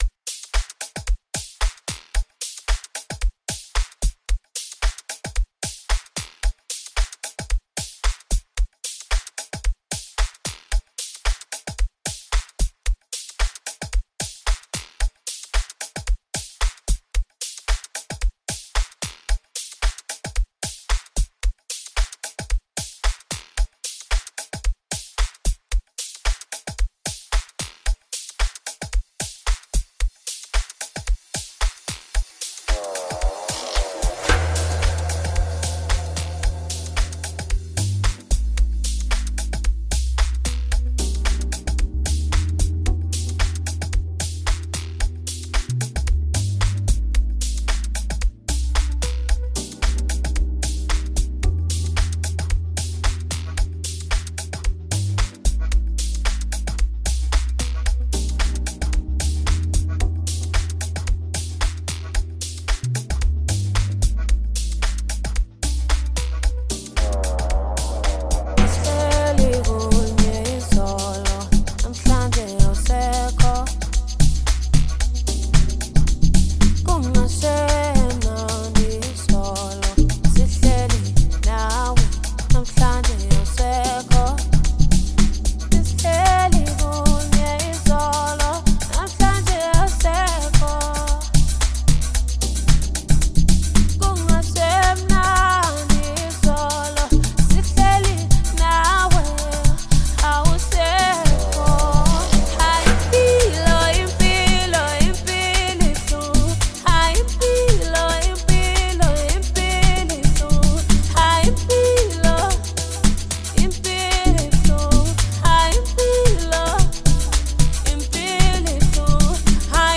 Amapiano’s track